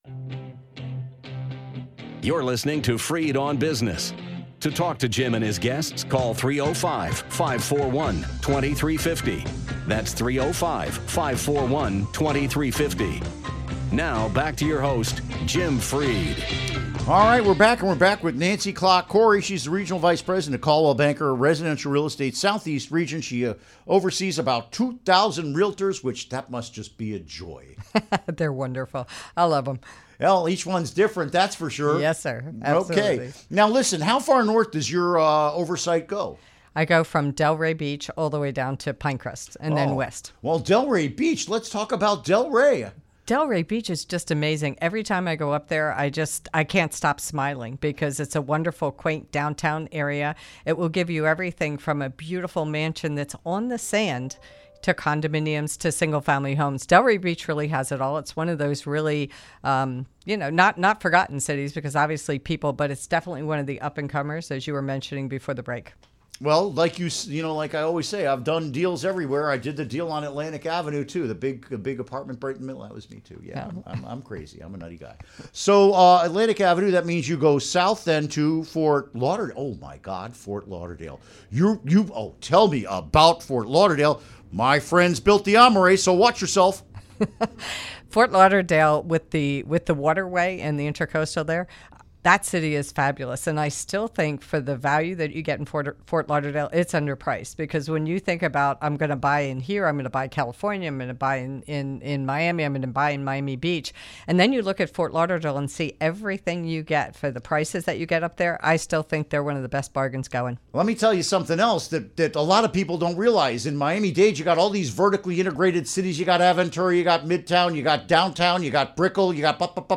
Interview Segment Click here to download Part 1 (To download, right-click and select “Save Link As”.)